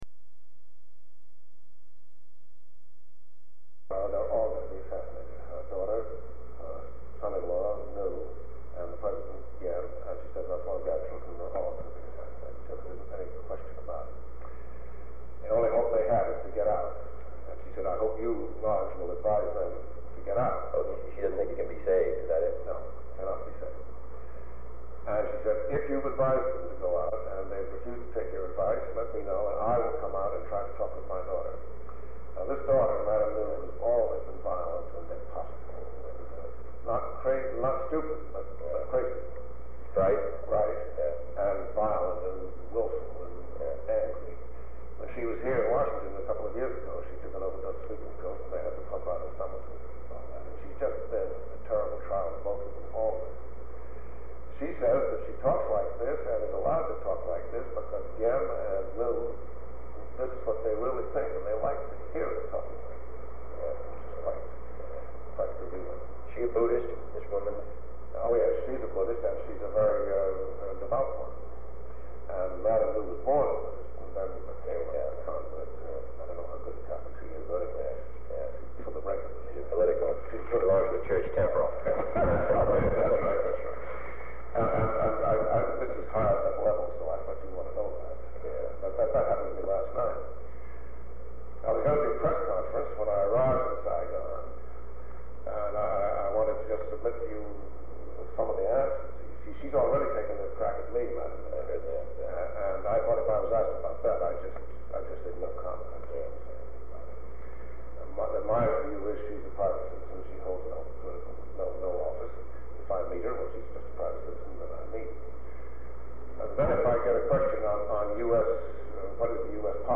Sound recording of a meeting held on August 15, 1963, between President John F. Kennedy and newly appointed United States Ambassador to South Vietnam Henry Cabot Lodge. Shortly before Ambassador Lodge is to depart for Vietnam, he and President Kennedy discuss plans he would like to implement as Ambassador and his opinions on the political situation in Vietnam with President of South Vietnam Ngo Dinh Diem and the Nhus.
Secret White House Tapes | John F. Kennedy Presidency Meetings: Tape 104/A40.